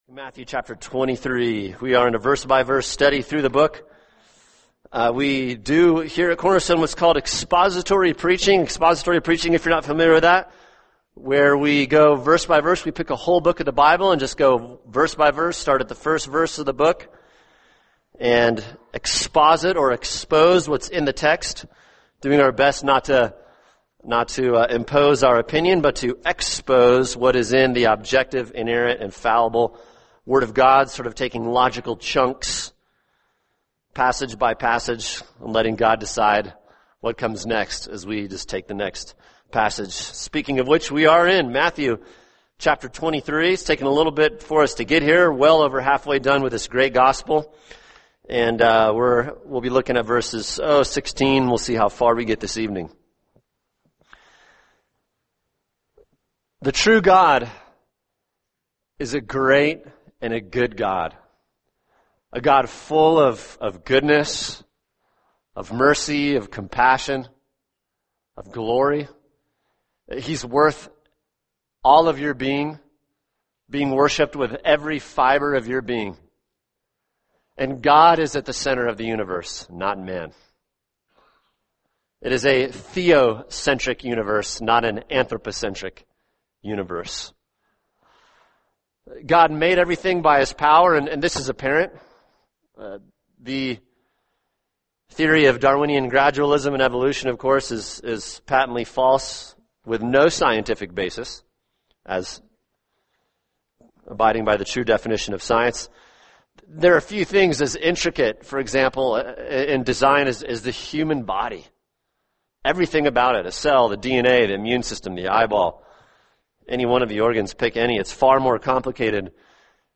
[sermon] Matthew 23:16-28 The Kind Of Leader God Condemns – Part 2 | Cornerstone Church - Jackson Hole